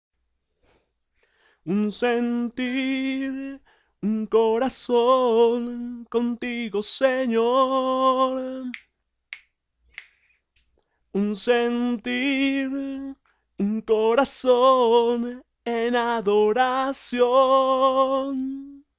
2a Voz Precoro Hombre